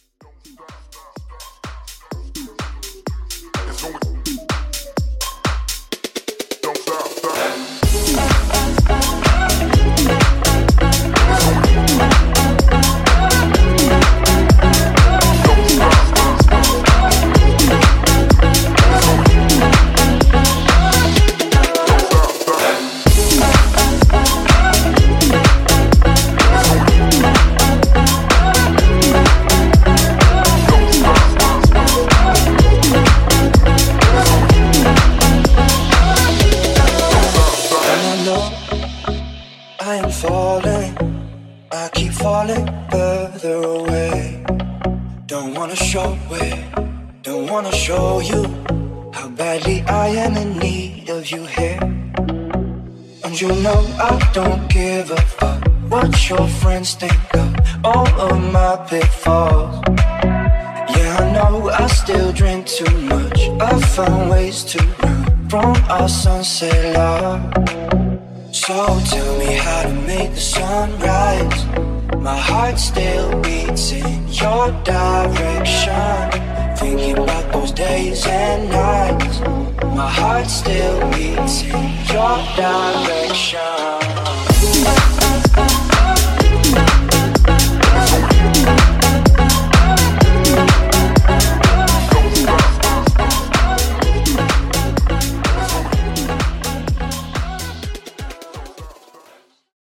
Extended Club Edit)Date Added